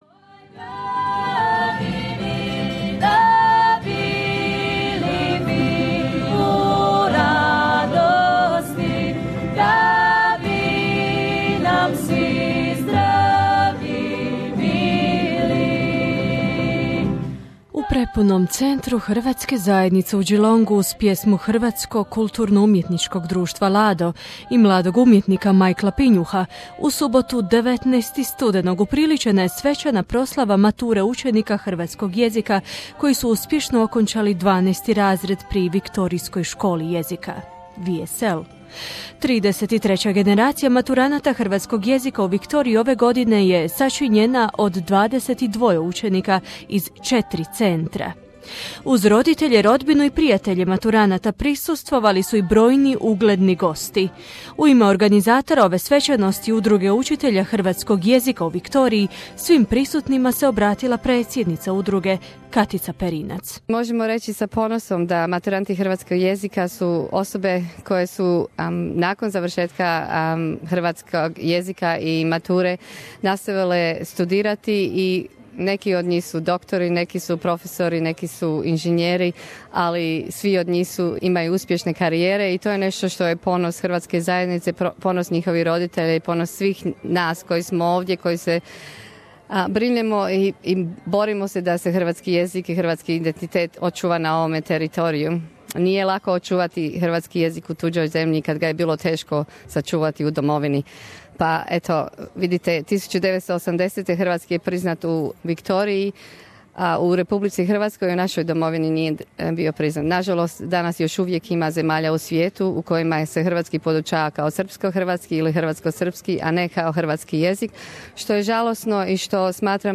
Year 12 students graduated at Victorian School of Languages (VSL).
Graduation ceremony 2016.